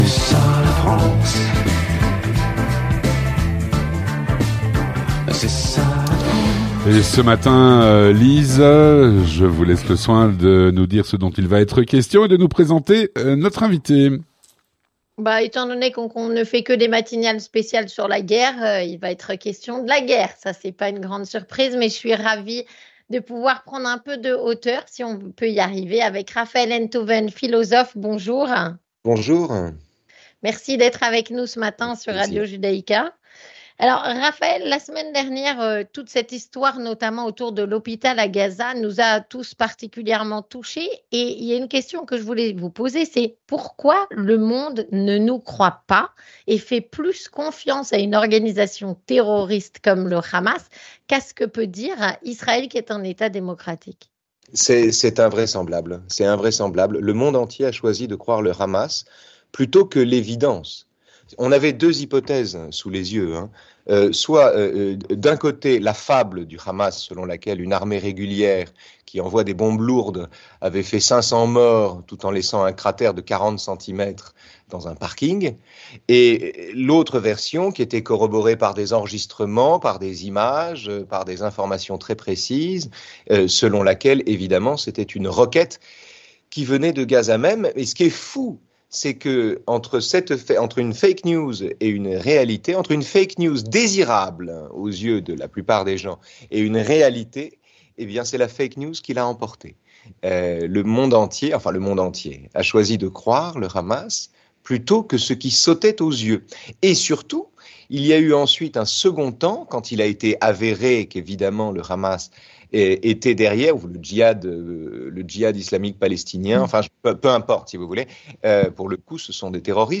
Avec Raphaël Enthoven, Philosophe et écrivain